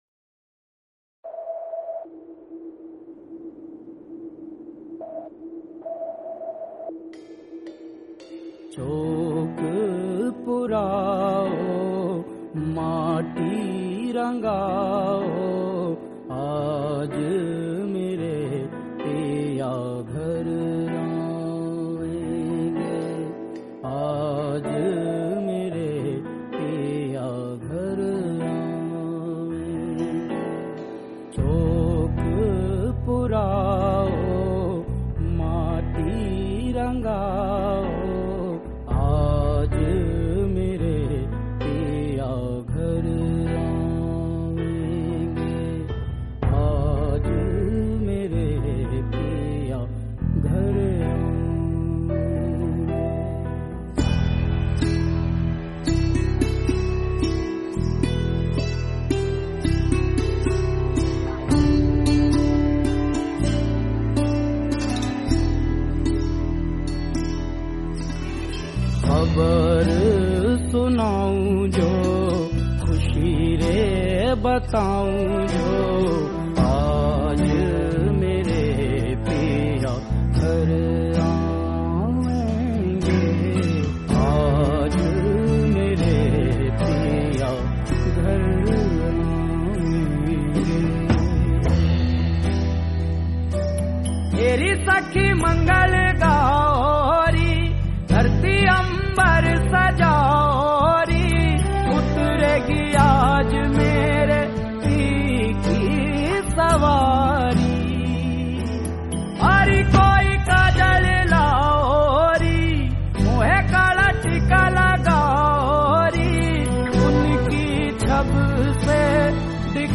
Releted Files Of Devotional